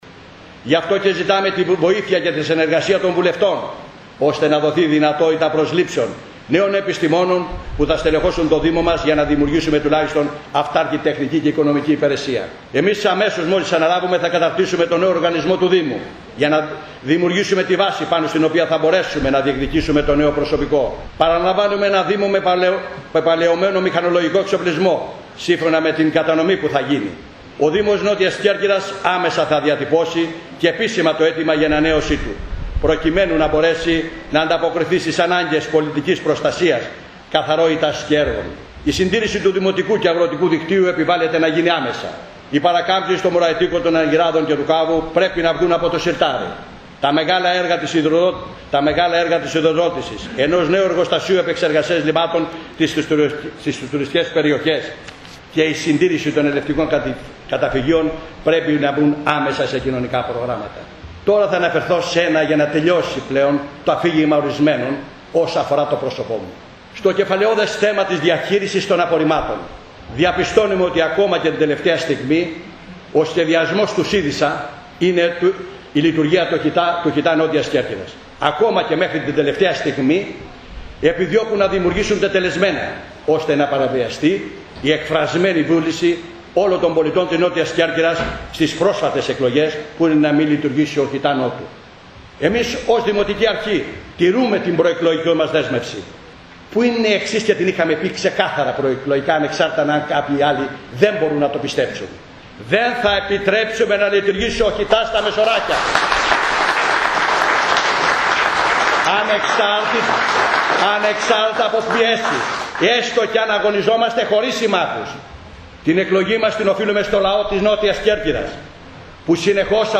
Σε πανηγυρικό κλίμα και με μεγάλες προσδοκίες πραγματοποιήθηκε η τελετή ορκωμοσίας του Δημάρχου και των δημοτικών συμβούλων Νότιας Κέρκυρας το περασμένο Σάββατο στις Αλυκές Λευκίμμης. Στην ομιλία του ο Δήμαρχος Κώστας Λέσσης υπογράμμισε την πρόθεση του για συνεργασία με όλες τις παρατάξεις και κάλεσε συμβολικά στο τέλος της ομιλία του, τους επικεφαλής των παρατάξεων της αντιπολίτευσης στη σκηνή.